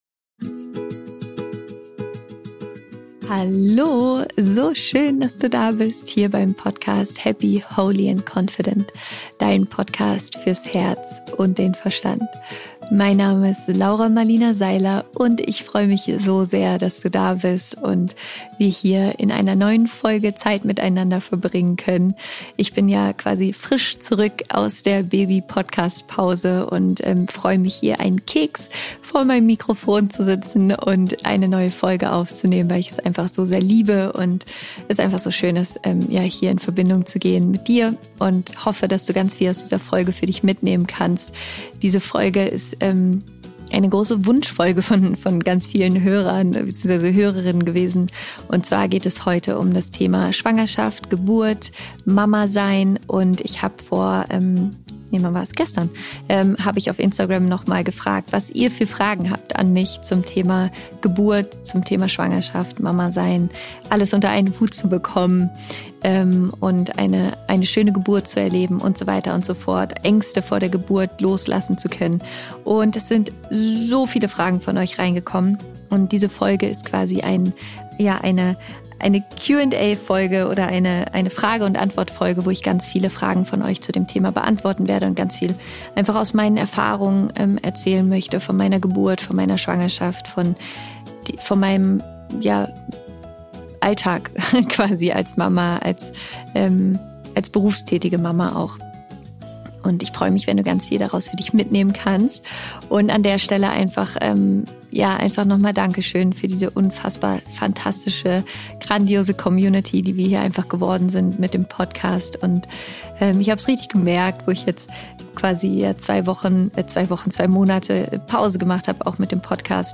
Deswegen habe ich eine neue Q&A Folge aufgenommen, in der ich eure meist gestellten Fragen zum Thema beantworte.